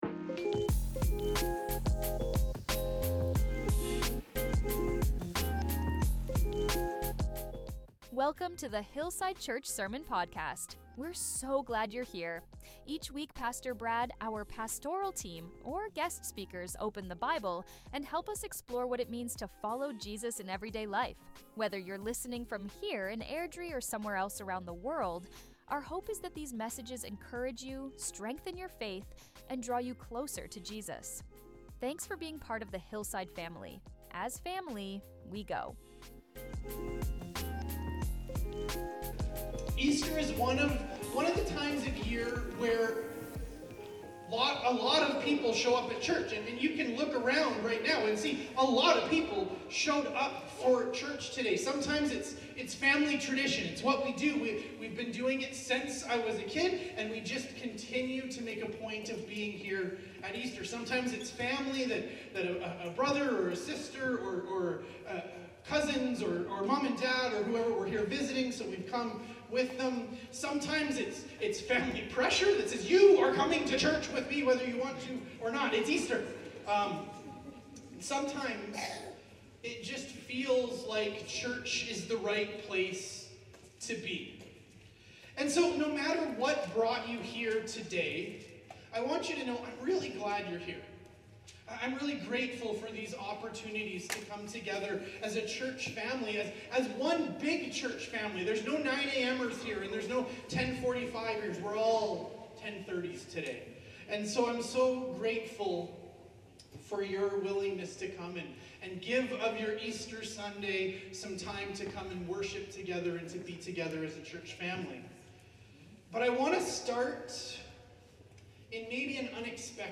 Our Sermons | HILLSIDE CHURCH
In this Easter Sunday message from our Because He Lives series, Because He Lives… We Can Have New Life, we celebrated the resurrection not just as something that happened, but as an invitation extended to each of us.